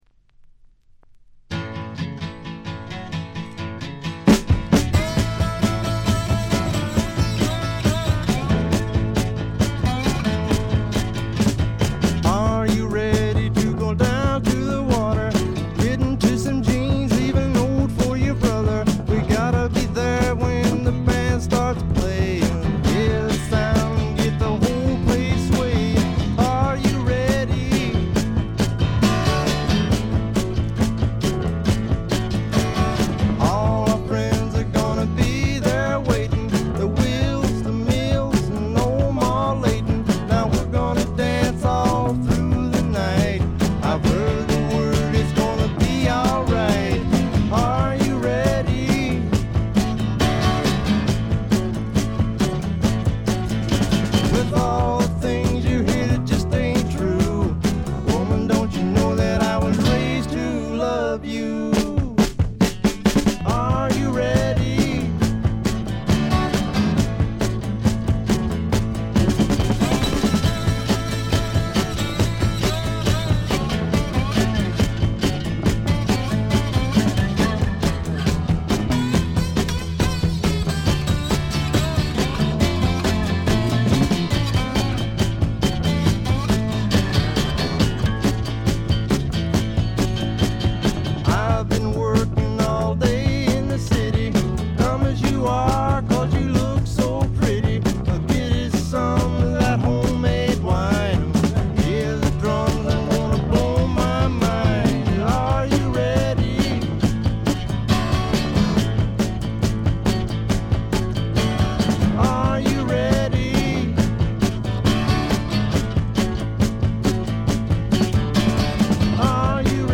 部分試聴ですがごくわずかなノイズ感のみ。
質感は哀愁のブリティッシュ・スワンプそのまんまであります。
試聴曲は現品からの取り込み音源です。